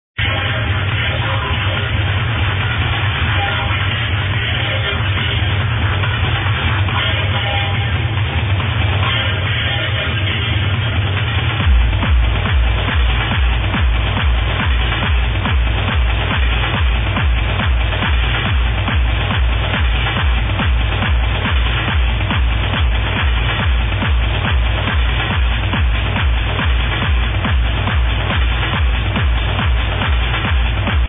this is hard techno